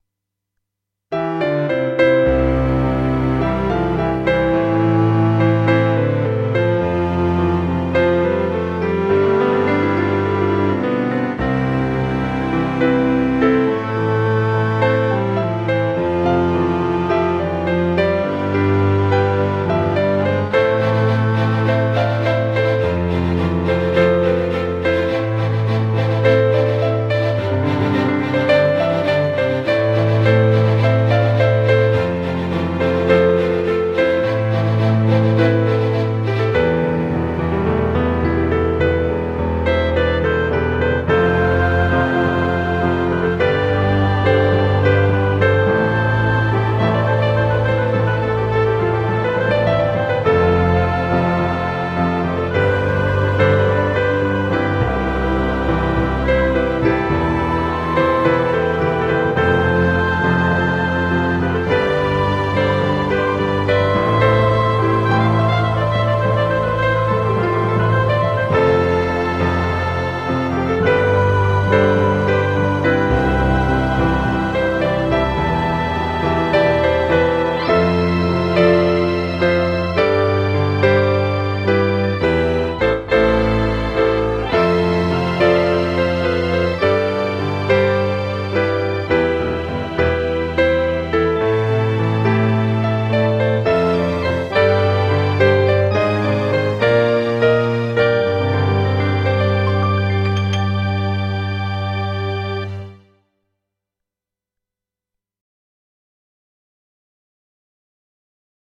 -Instrument: Kurzweil SP3X for all tracks.
I believe that I may have issues mainly on EQ and high Peaks, in this case I think that the maximum peak is not so bad (reaching -0.1db or +0.0db), but this is just on specific parts of the song, while in the rest I have an inconsisten waveform with gains of -1.5dB or lower.